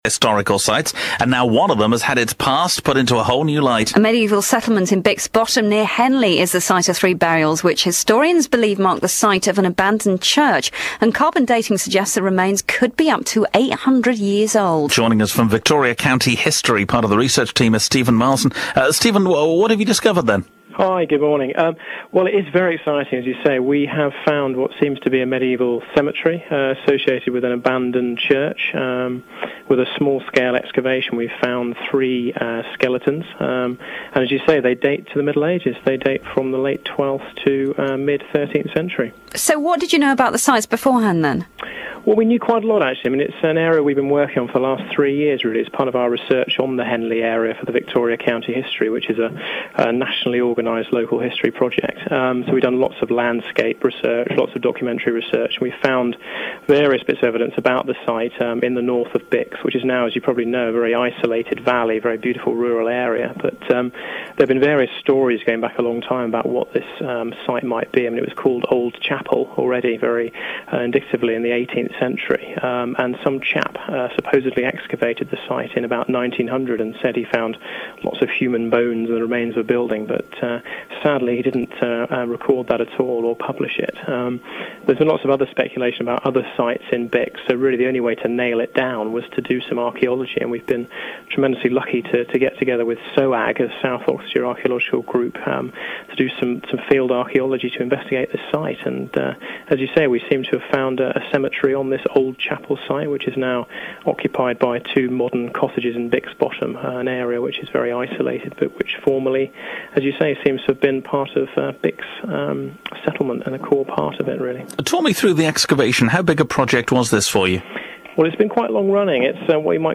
interview 1 - archaeology (audio in mp3 format - 5MB).